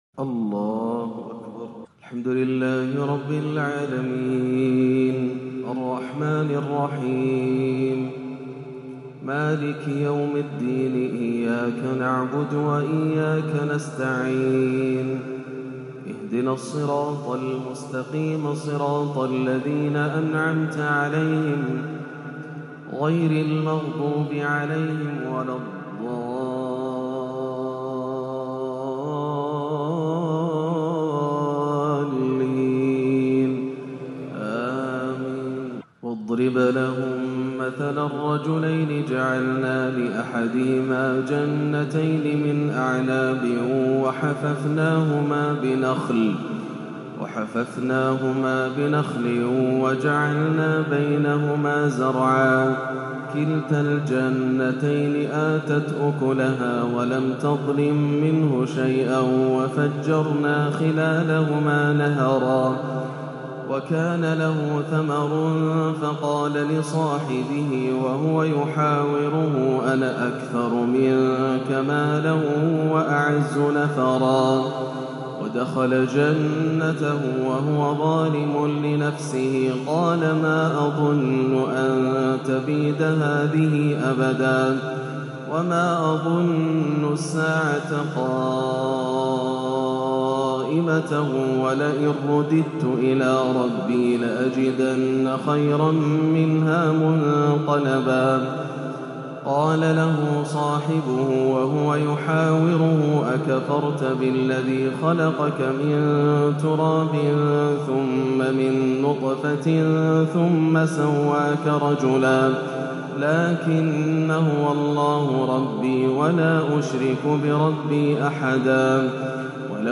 (ووضع الكتاب فترى المجرمين مشفقين) في أروع وأخشع تلاوة يرتل شيخنا من سورة الكهف - السبت 18-12 > عام 1439 > الفروض - تلاوات ياسر الدوسري